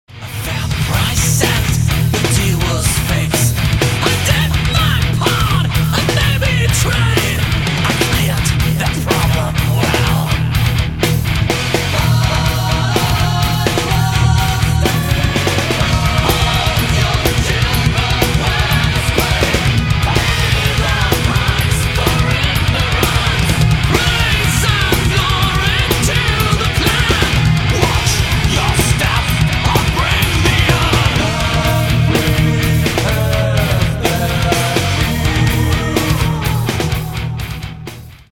power metal